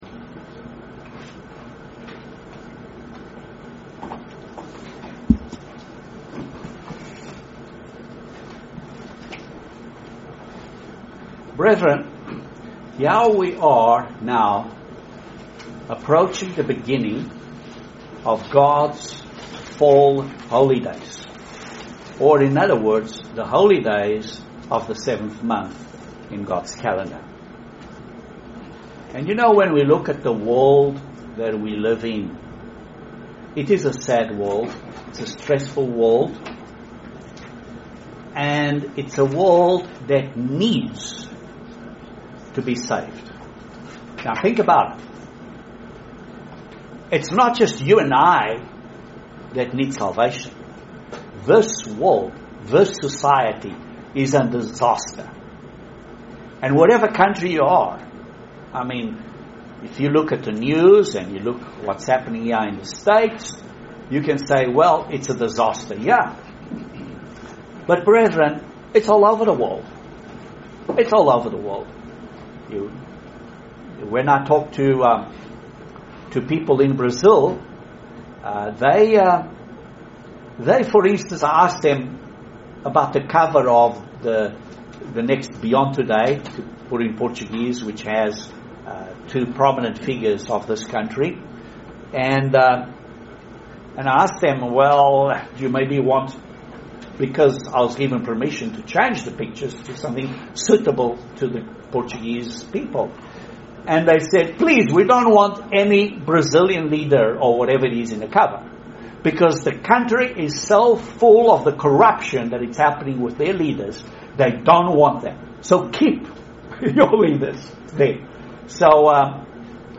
Excellent Sermon on God's Fall Holy Day Season. These are the Feasts of the Lord.